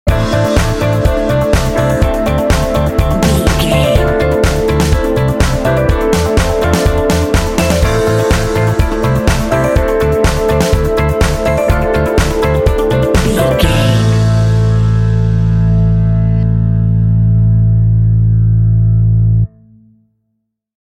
Epic / Action
Aeolian/Minor
funky
smooth
groovy
driving
synthesiser
drums
strings
piano
electric guitar
bass guitar
electric piano
indie
alternative rock
contemporary underscore